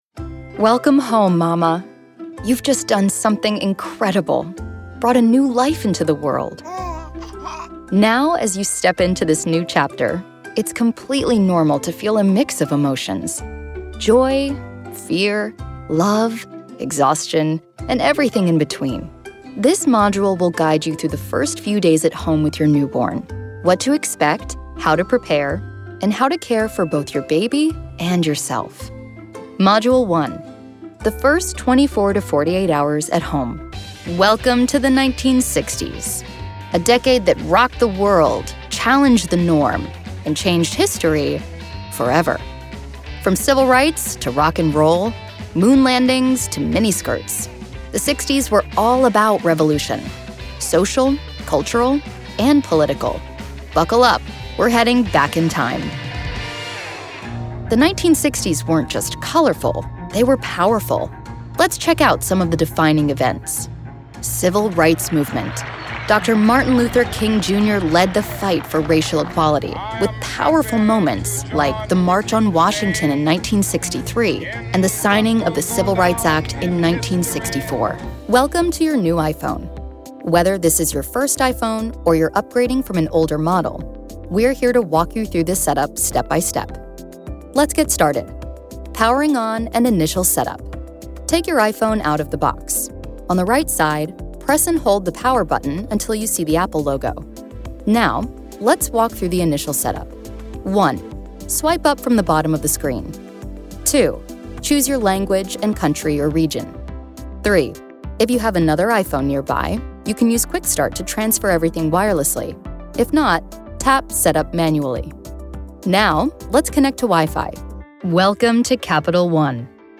Relatable, Authentic Vibe :)
English - USA and Canada
Young Adult
E-Learning